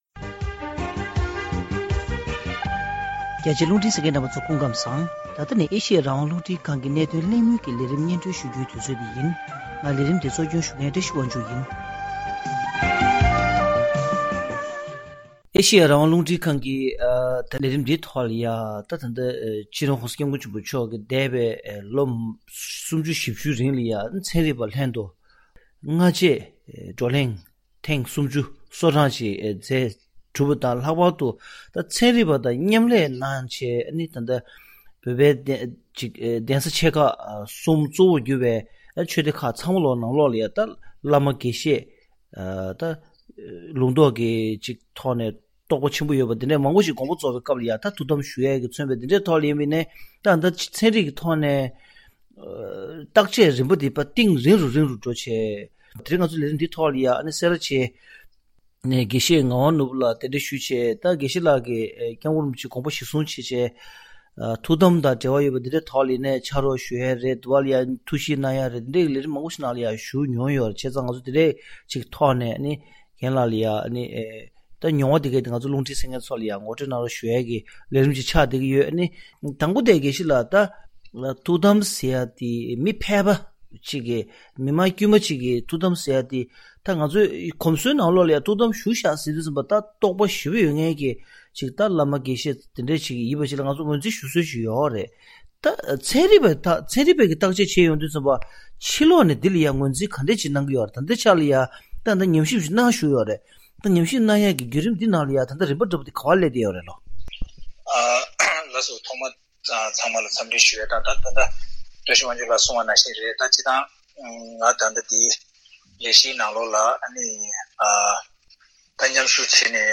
ད་རེས་ཀྱི་གནད་དོན་གླེང་མོལ་གྱི་ལས་རིམ་འདིའི་ནང་།